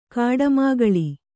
♪ kāḍamāgaḷi